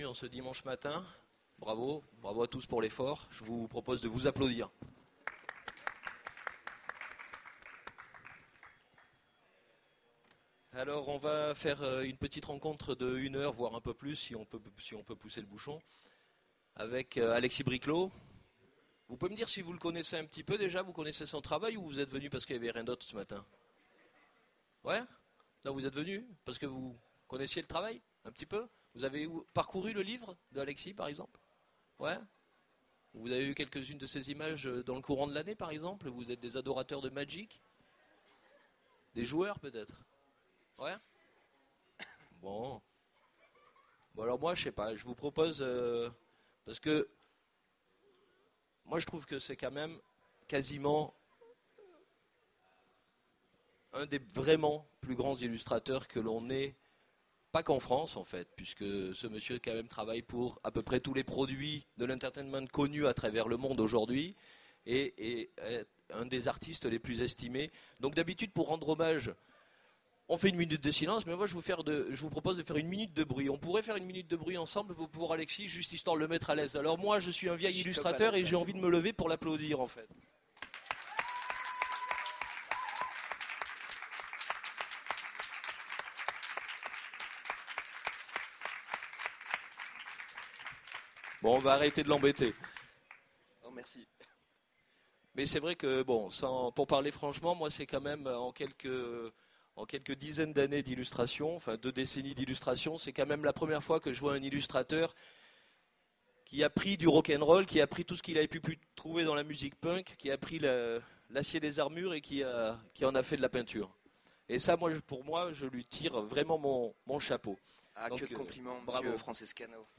Utopiales 2010 : Conférence
Mots-clés bande dessinée Rencontre avec un auteur Conférence Partager cet article